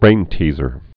(brāntēzər)